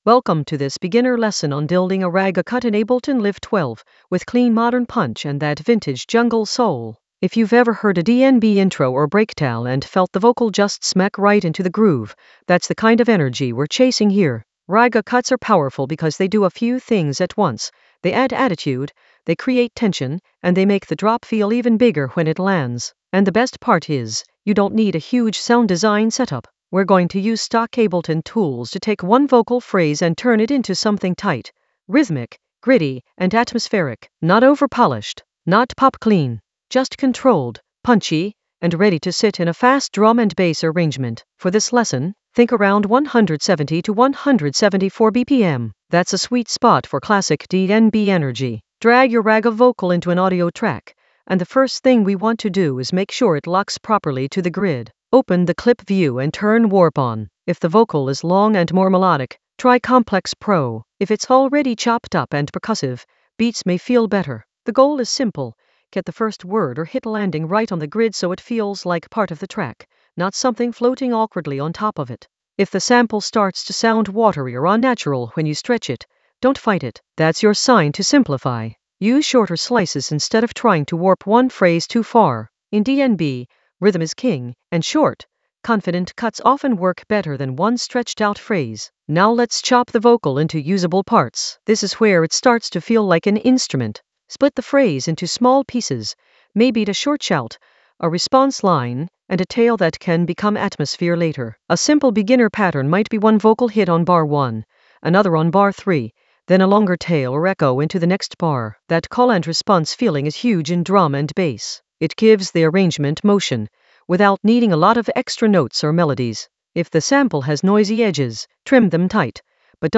An AI-generated beginner Ableton lesson focused on Ragga cut in Ableton Live 12: clean it with modern punch and vintage soul in the Atmospheres area of drum and bass production.
Narrated lesson audio
The voice track includes the tutorial plus extra teacher commentary.